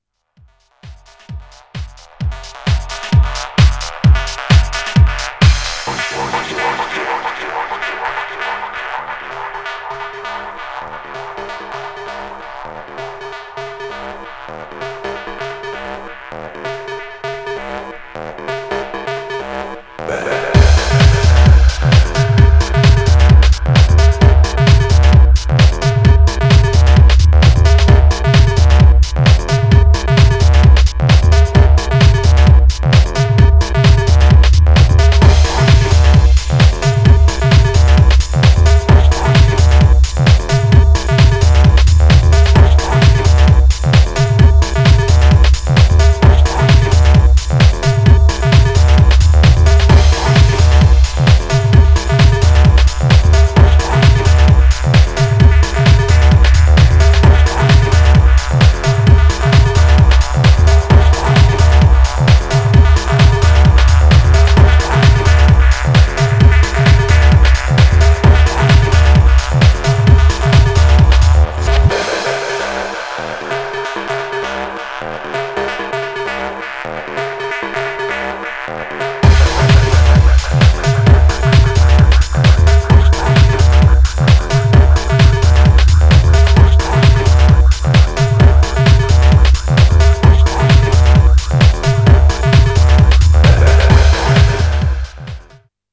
Styl: Progressive, House